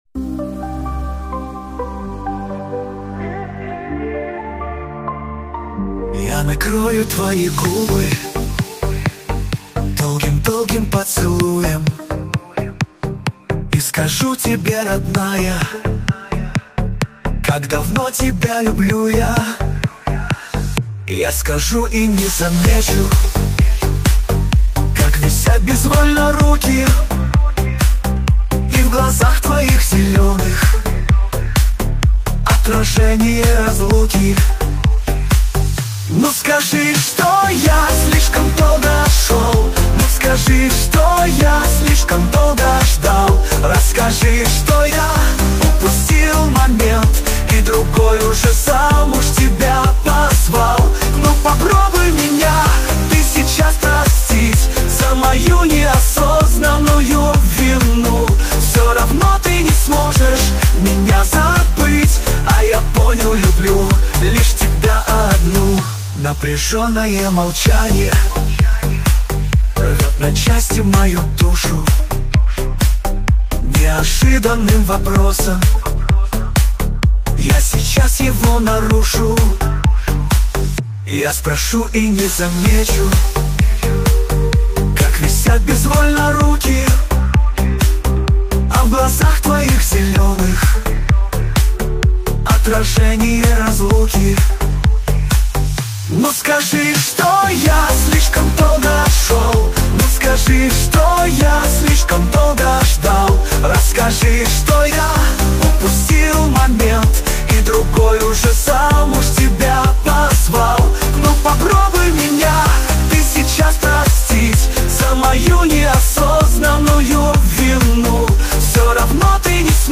релиз записан голосом искусственного интеллекта